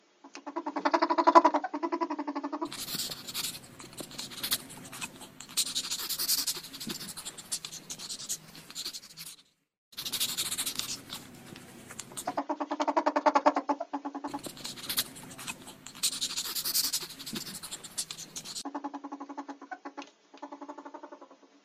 Звуки, издаваемые хорьками можете послушать онлайн, а при необходимости загрузить на телефон, планшет или компьютер бесплатно.
1. Как говорят хорьки, голос хорька